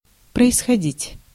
Ääntäminen
IPA: [prəɪsxɐˈdʲitʲ]